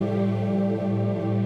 Downtempo/Chillout, Ambient Genre